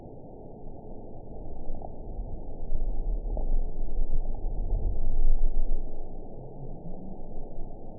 event 922784 date 04/05/25 time 15:39:04 GMT (2 months, 1 week ago) score 5.98 location TSS-AB06 detected by nrw target species NRW annotations +NRW Spectrogram: Frequency (kHz) vs. Time (s) audio not available .wav